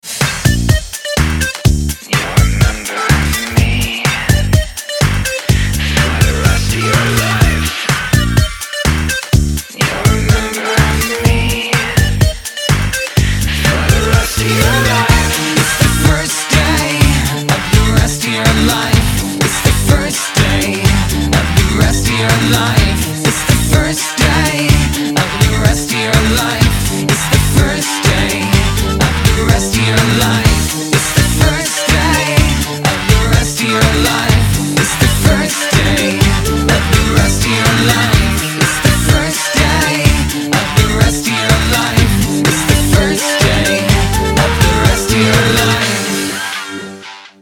dance
Electronic
Electronica
club